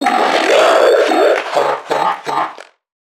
NPC_Creatures_Vocalisations_Infected [127].wav